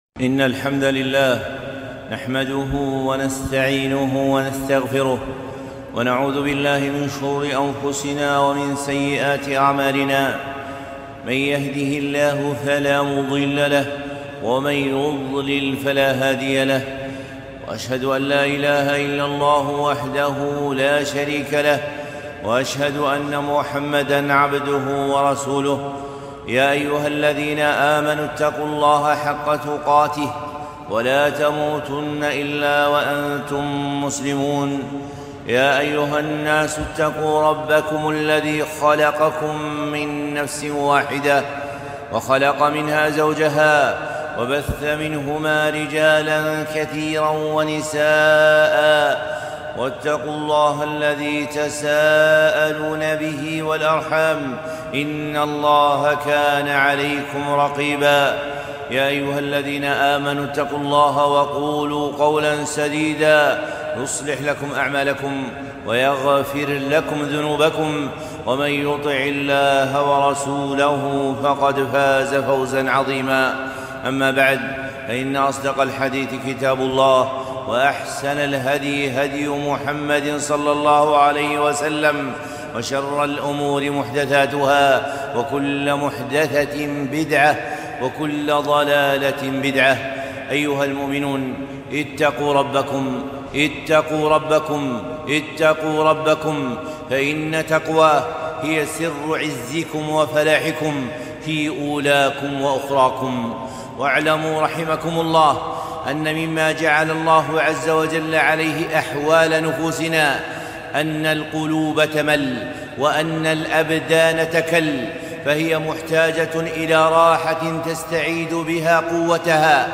خطبة - روحوا القلوب..